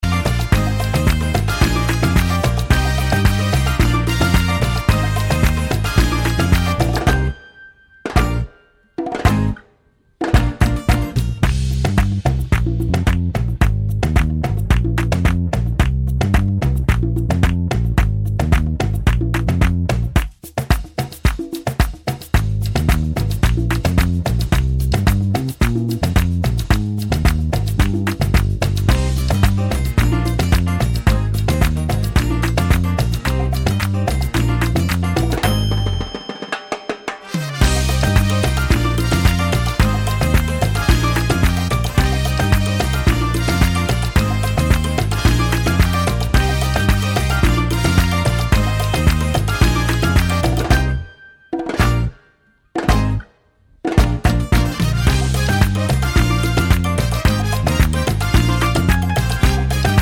no Backing Vocals Pop (2020s) 2:39 Buy £1.50